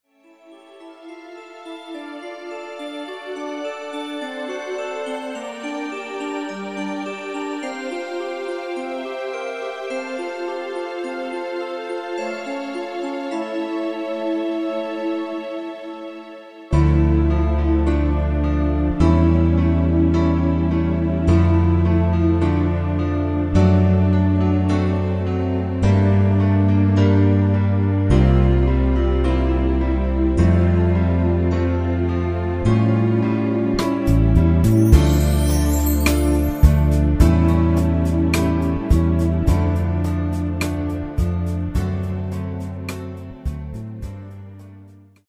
DEMO MP3 MIDI